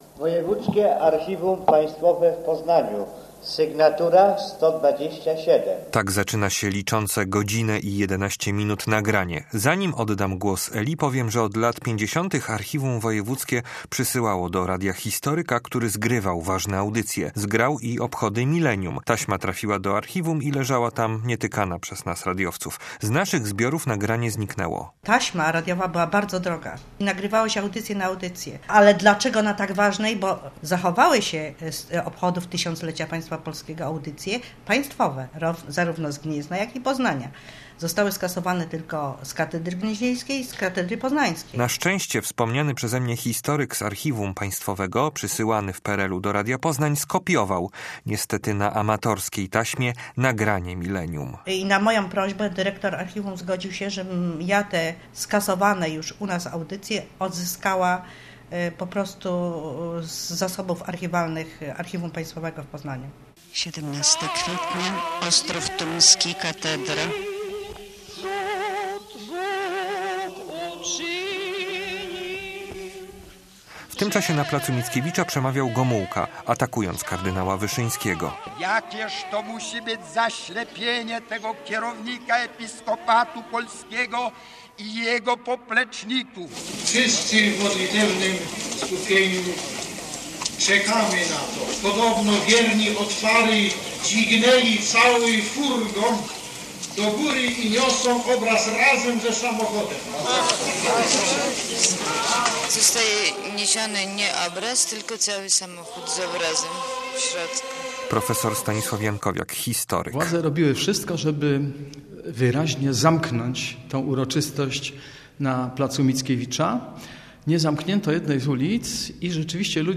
Teraz możemy usłyszeć nie tylko głos kardynała Stefana Wyszyńskiego, ale potwierdzić krążącą po mieście informację o tym, że poznaniacy nieśli samochód z kopią Matki Boskiej Częstochowskiej.